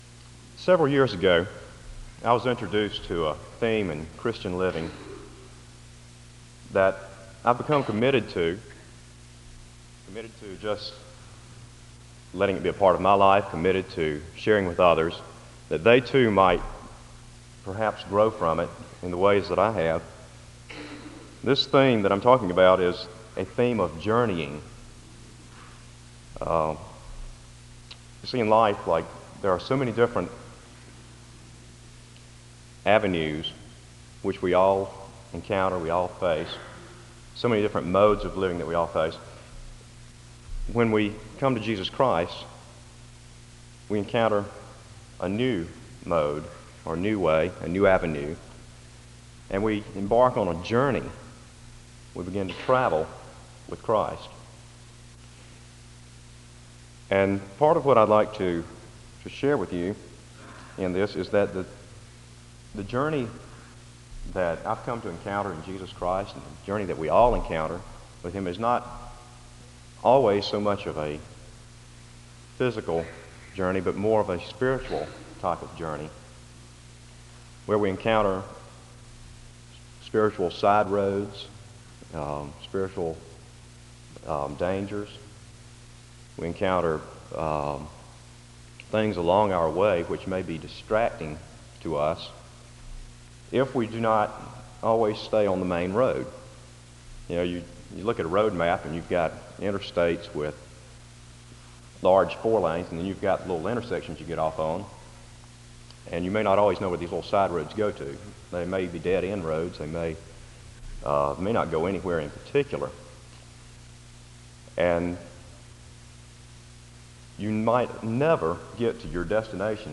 SEBTS Chapel
• Wake Forest (N.C.)